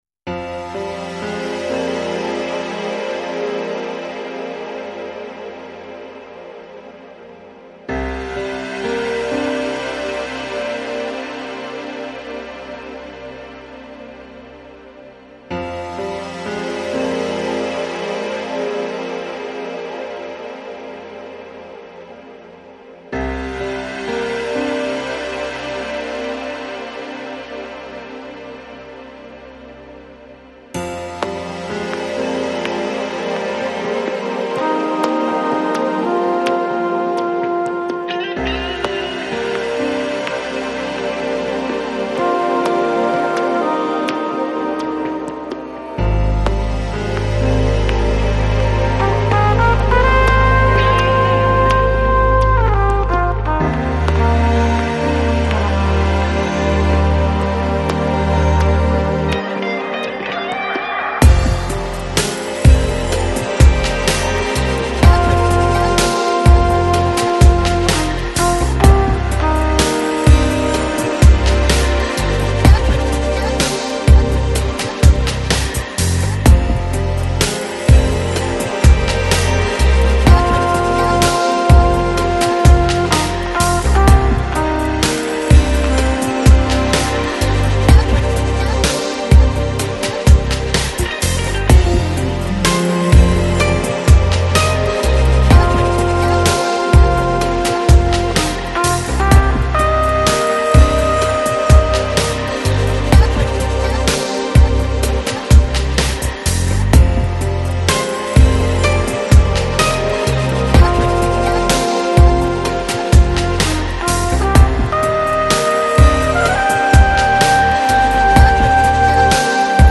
Electronic, Chill Out, Lounge Год издания